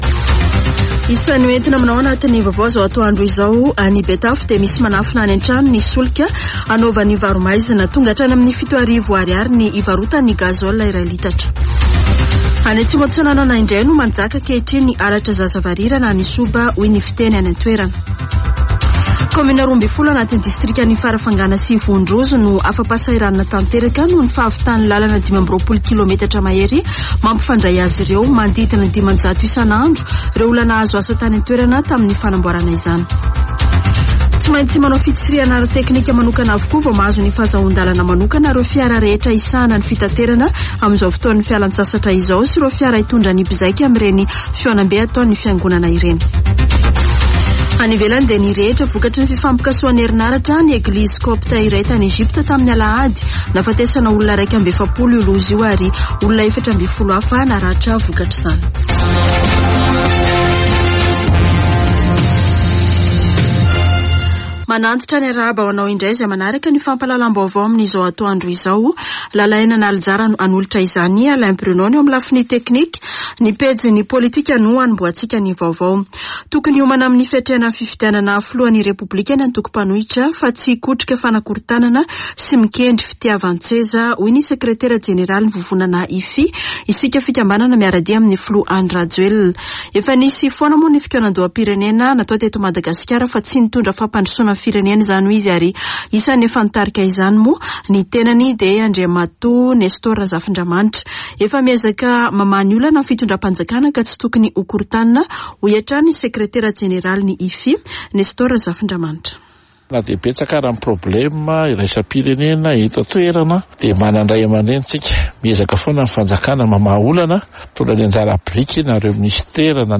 [Vaovao antoandro] Alarobia 17 aogositra 2022